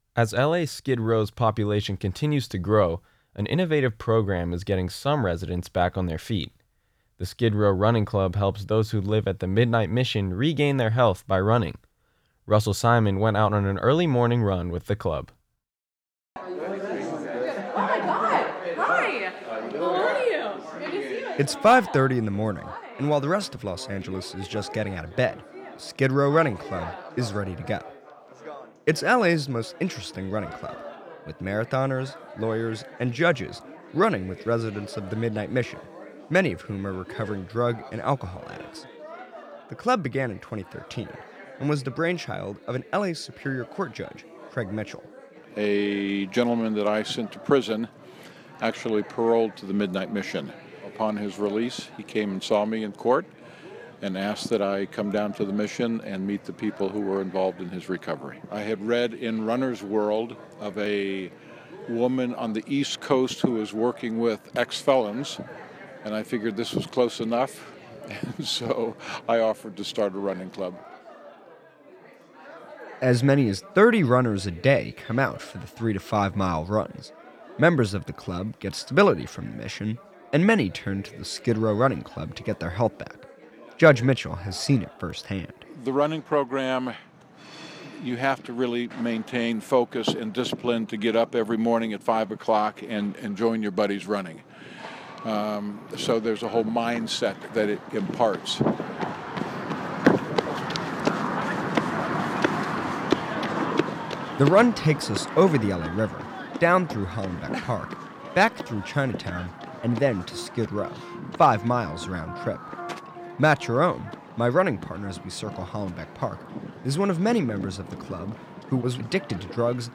went out on an early morning run with the club.